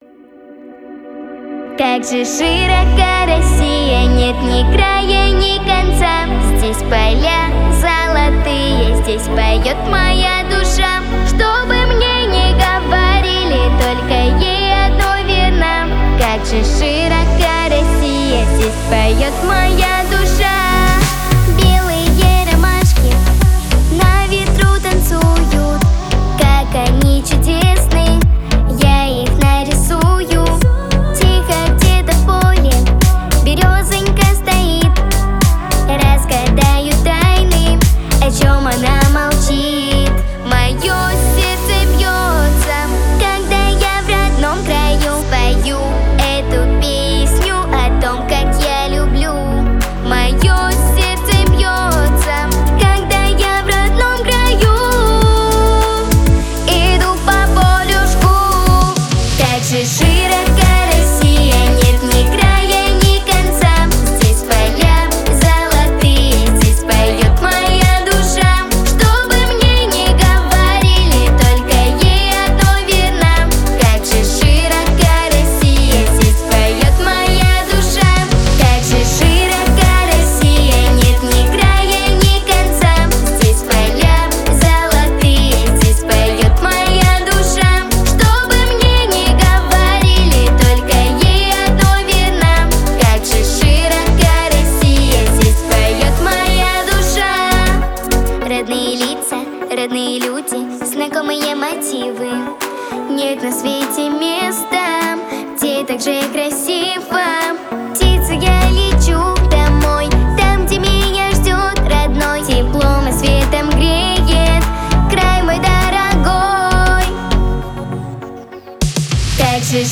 • Качество: Хорошее
• Жанр: Детские песни
патриотическая
Детская песня